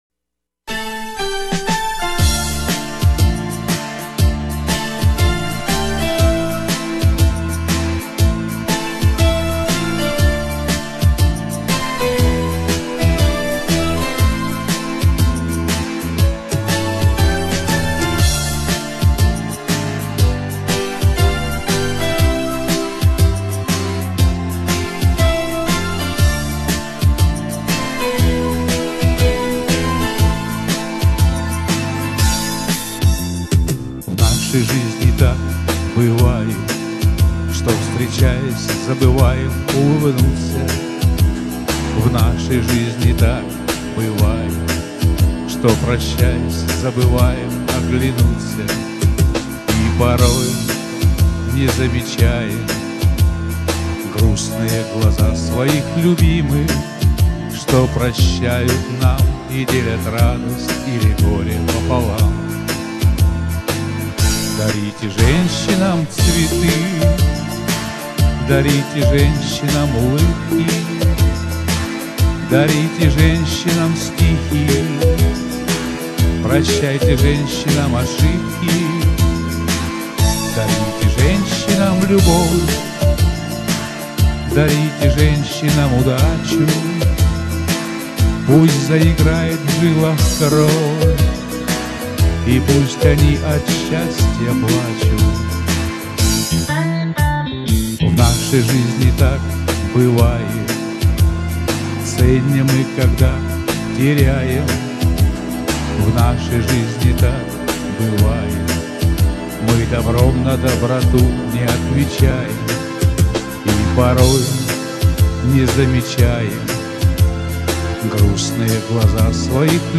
Песни такие серьёзные на простой шансоновый ритм.
Звук глуховатый чуток согласен.